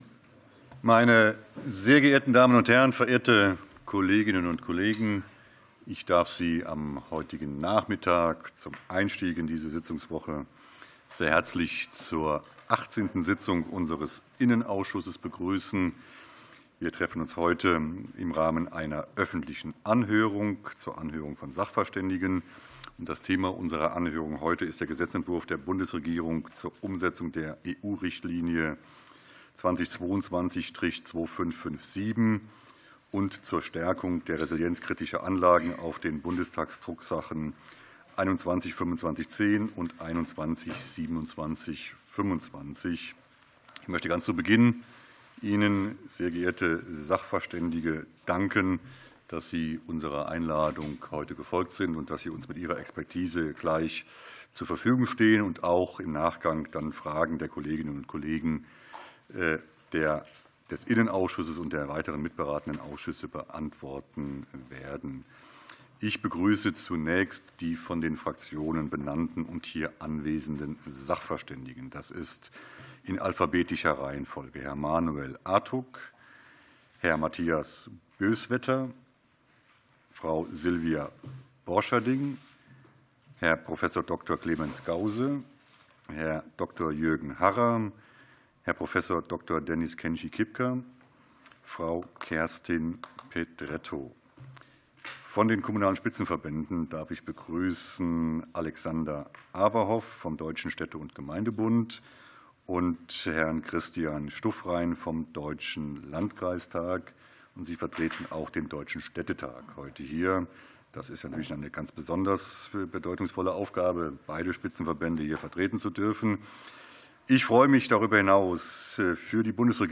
Anhörung des Innenausschusses